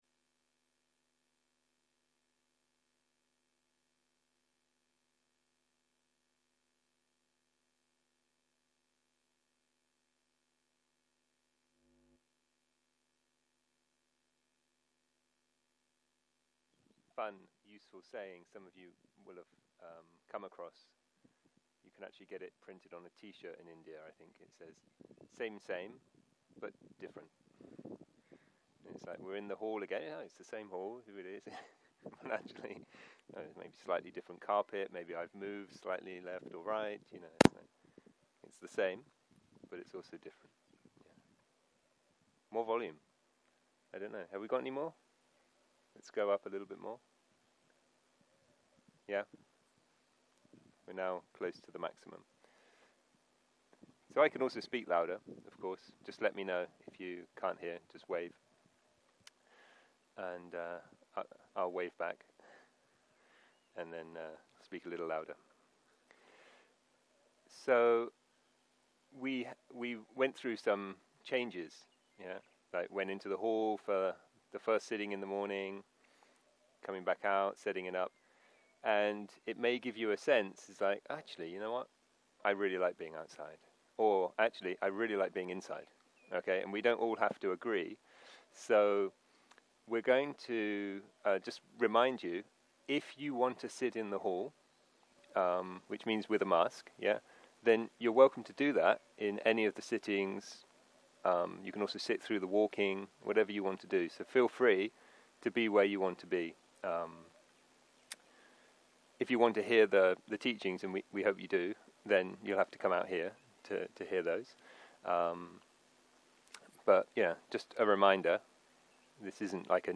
סוג ההקלטה: מדיטציה מונחית
ריטריט פסח